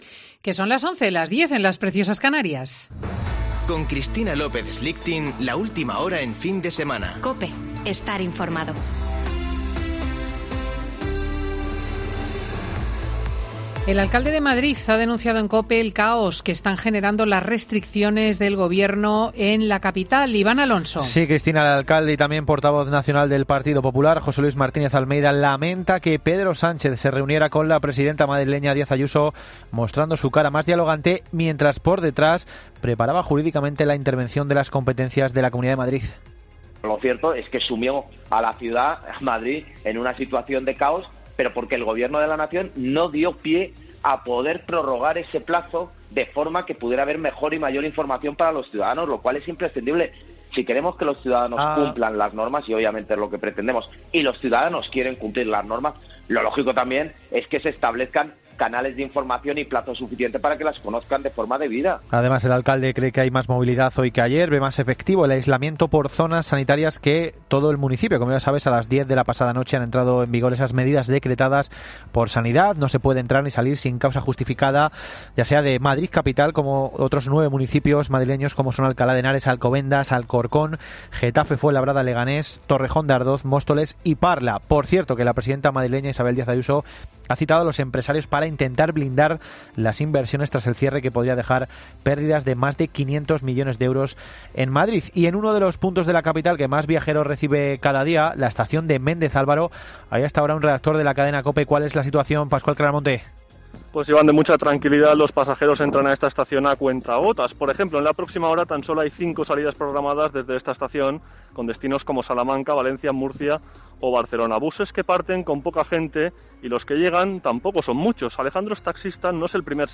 Boletín de noticias de COPE del 3 de Octubre de 2020 a las 11.00 horas